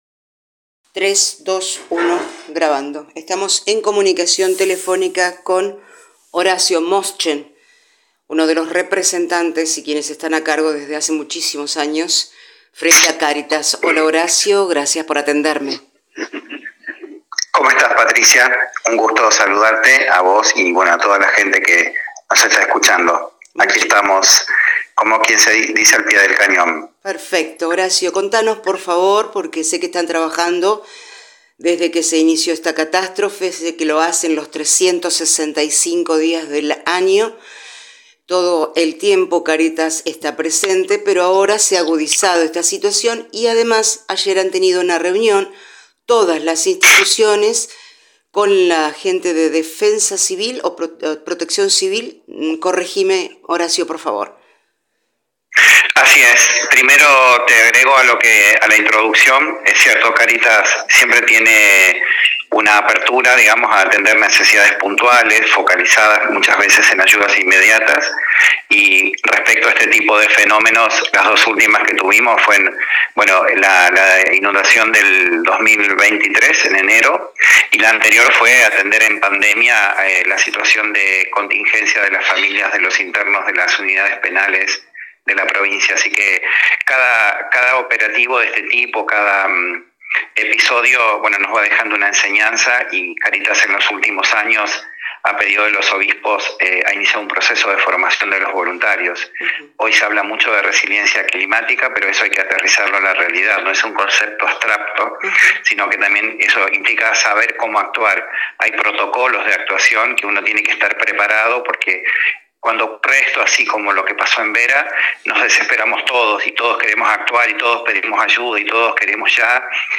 dialogamos